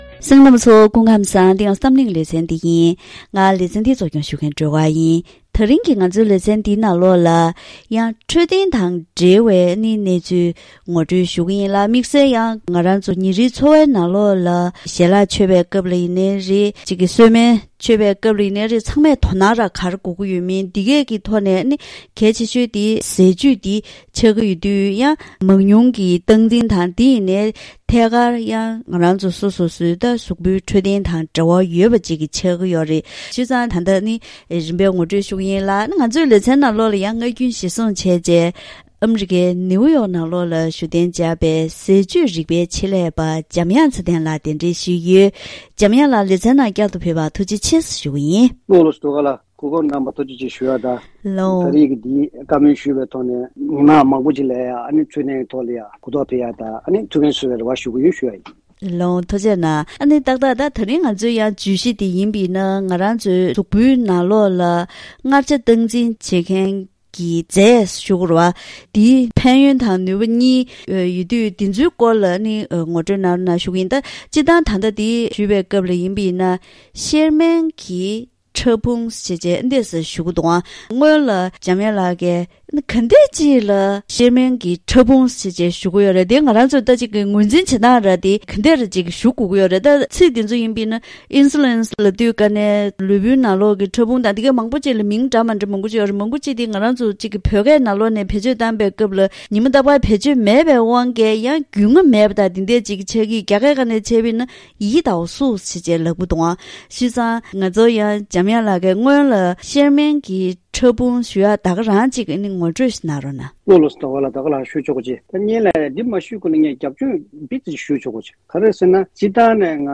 ད་རིང་གི་གཏམ་གླེང་ཞལ་པར་ལེ་ཚན་ནང་ལུས་པོའི་ནང་གནས་པའི་མངར་ཆ་སྟངས་འཛིན་གྱི་རྫས་གཤེར་རྨེན་ཕྲ་ཕུང་གི་ནུས་པ་ཤོར་ཚེ་ནད་གཞི་མང་པོ་ཞིག་ཕོག་སྲིད་པས། ཉིན་རེའི་འཚོ་བའི་ནང་ཟས་སྤྱོད་བསྟེན་སྟངས་དང་། སྔོན་འགོག་ཡོང་ཐབས་སོགས་ཀྱི་སྐོར་ལ་ཟས་བཅུད་རིག་པའི་ཆེད་ལས་པ་དང་ལྷན་དུ་བཀའ་མོལ་ཞུས་པ་ཞིག་གསན་རོགས་གནང་།